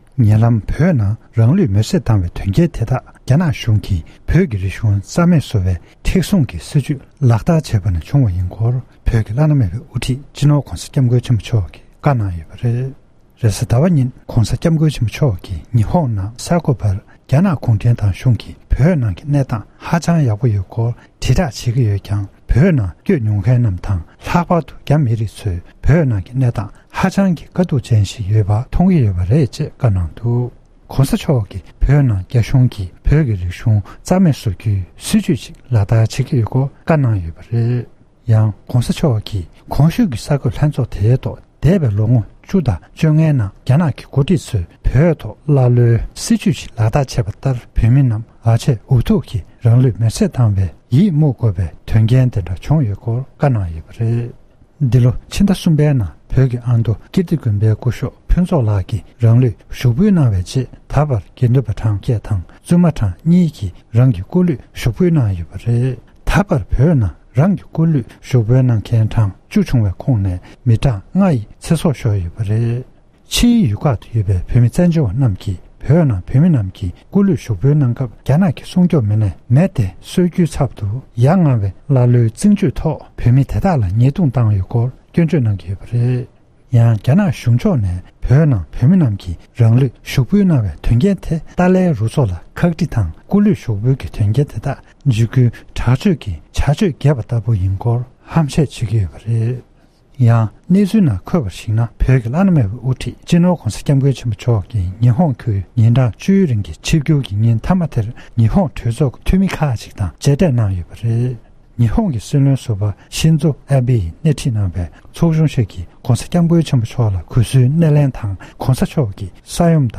༸གོང་ས་མཆོག་ནས་ཉི་ཧོང་གི་རྒྱལ་སར་བོད་ནང་གི་ཛ་དྲག་གནང་སྟངས་ཐོག་གསར་འགོད་ལྷན་ཚོགས་གནང་བ།
སྒྲ་ལྡན་གསར་འགྱུར། སྒྲ་ཕབ་ལེན།